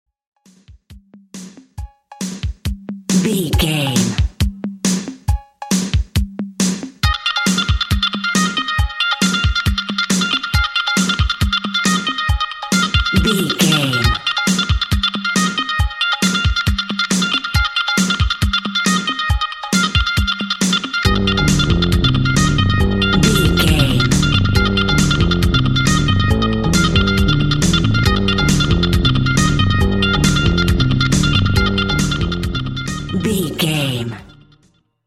Eighties Electro Pop.
Aeolian/Minor
Slow
melancholy
futuristic
hypnotic
dreamy
peaceful
smooth
groovy
drums
synthesiser
bass guitar
flute
saxophone
synth bass
synth lead